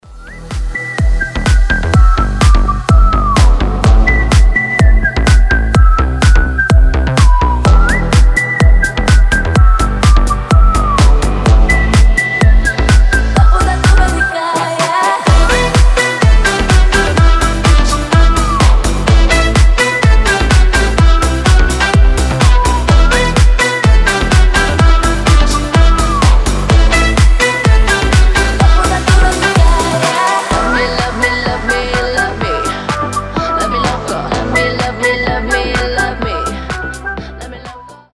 • Качество: 320, Stereo
свист
slap house